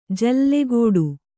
♪ jallegōḍu